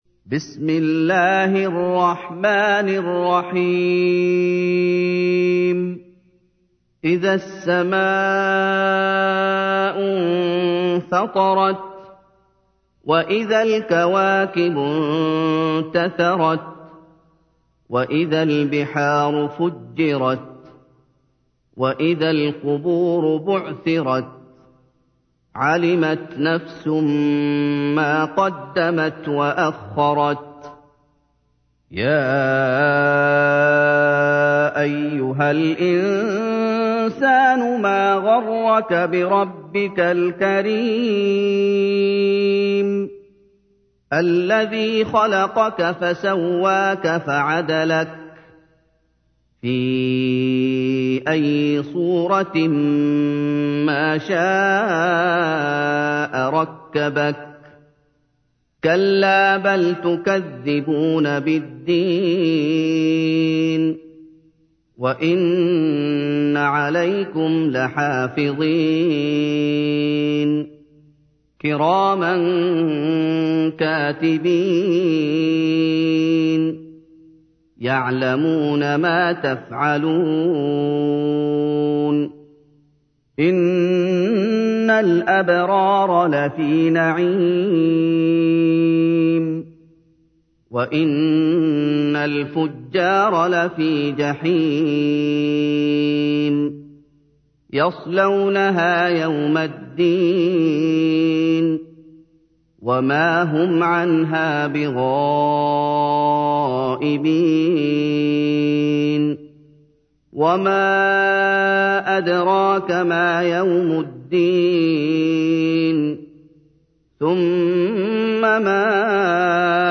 تحميل : 82. سورة الانفطار / القارئ محمد أيوب / القرآن الكريم / موقع يا حسين